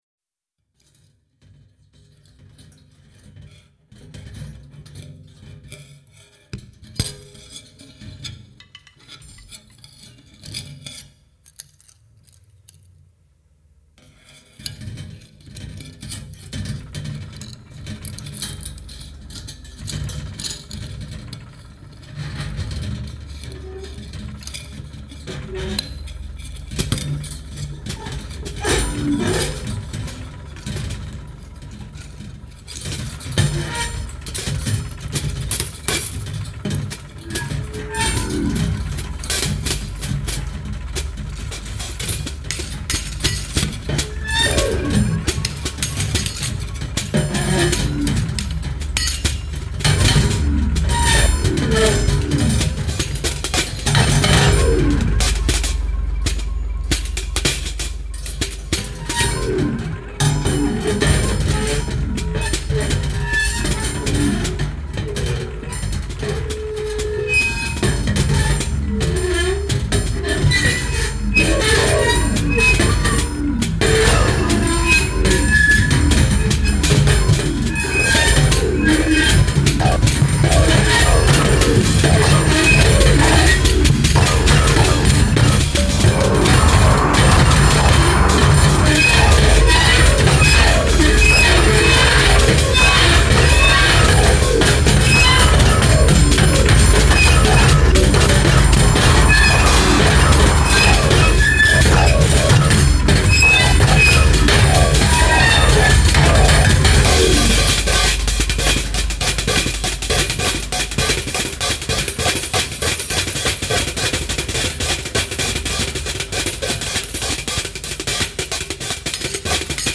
a work for prepared drumset and electronics